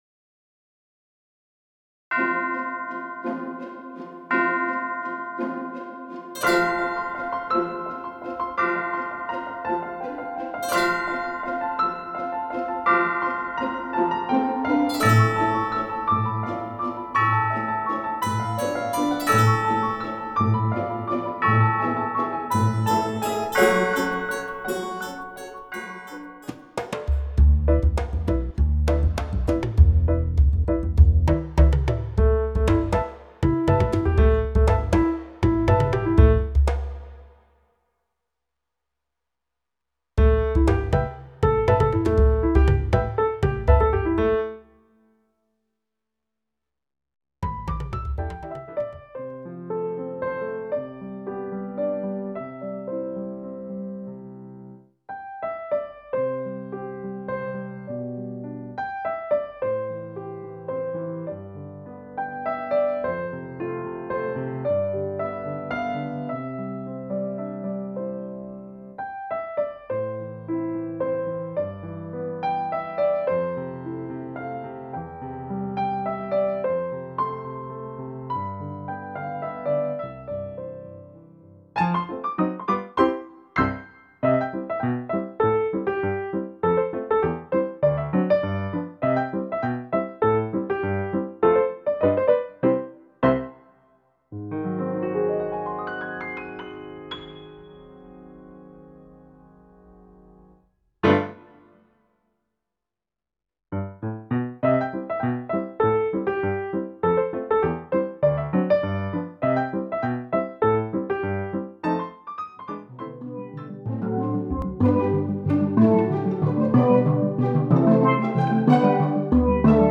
original music tracks